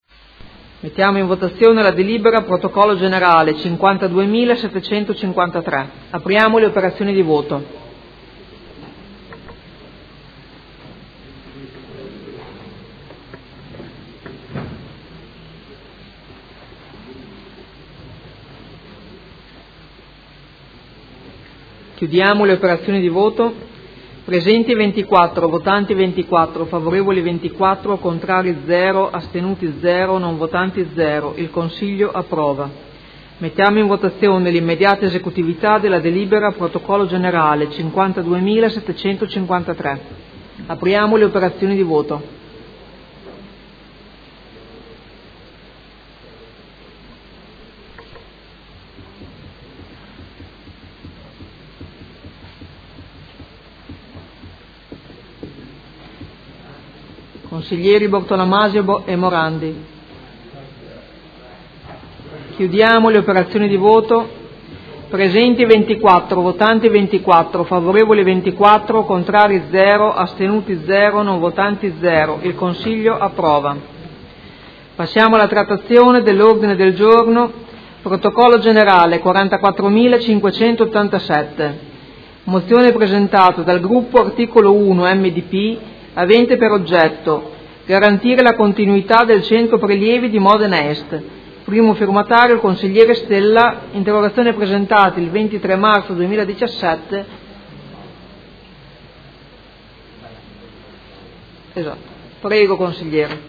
Seduta dell'11/05/2017 Mette ai voti. Regolamento comunale per l’accesso ai Centri socio-riabilitativi residenziali e diurni per disabili e criteri di contribuzione per concorrere al pagamento del servizio – Approvazione.
Presidentessa